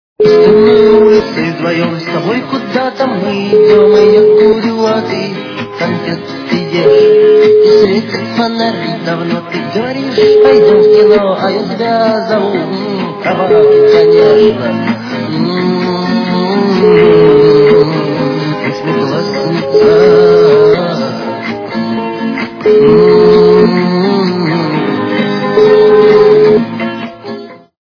російська естрада
качество понижено и присутствуют гудки